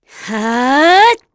Glenwys-Vox_Casting3_kr.wav